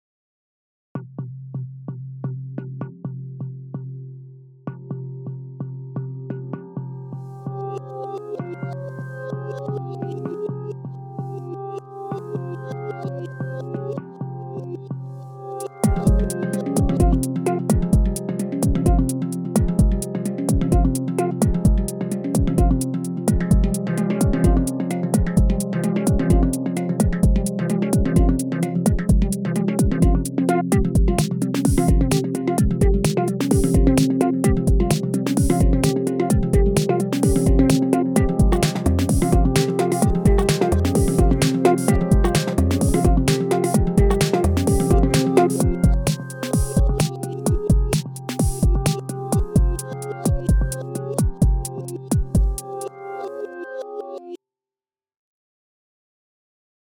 Hang Drum demo
Slapping or tapping the different divots around the outside of the metal drum produce different tones within a scale, rich with harmonics.
I recorded each strike using an AKG C414B and Beyerdynamic M160, using the mid/side micing technique which produces a recording with a wide stereo image, but which also sums perfectly to mono.
Click the media file on the right to hear a little ditty I knocked up using this instrument, and a beat from Maschine.
The instrument itself is in the key of C.
Hang-Drum-Ditty.mp3